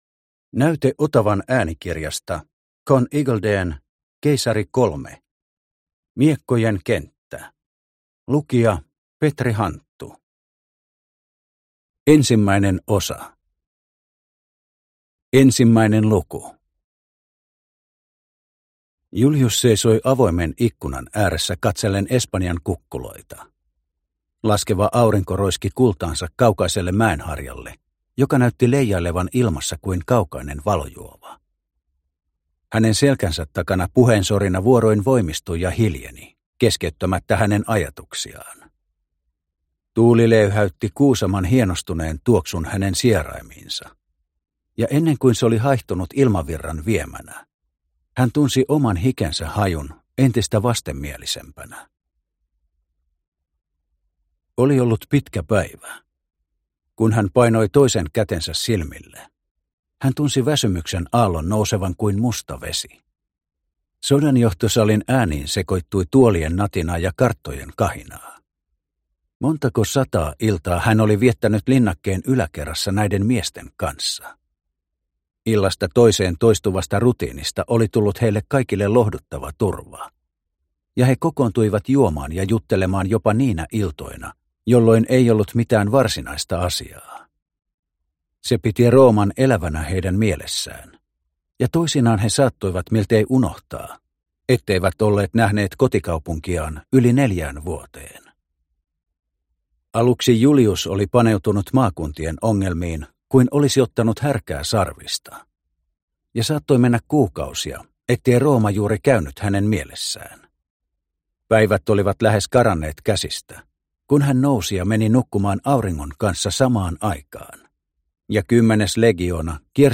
Keisari III. Miekkojen kenttä – Ljudbok – Laddas ner